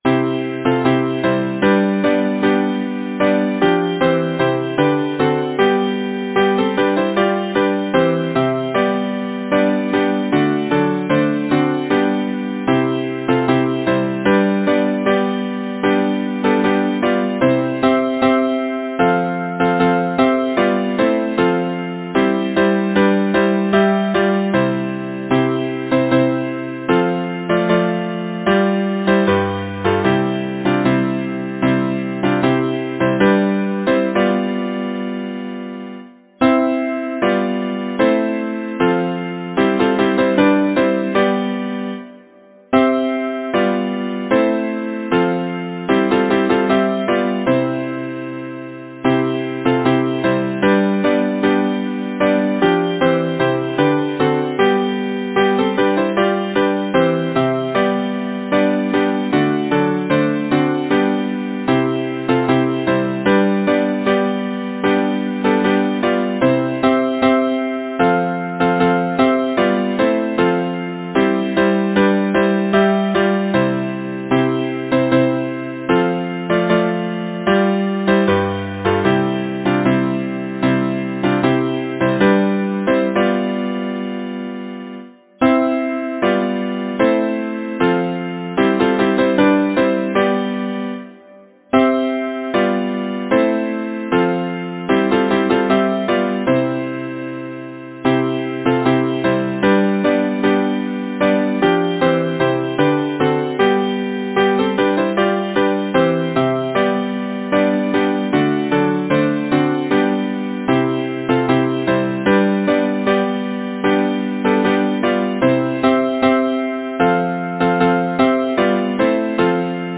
Title: Merry May Composer: Edward Roberts Lyricist: Fanny Crosby Number of voices: 4vv Voicing: SATB Genre: Secular, Partsong
Language: English Instruments: A cappella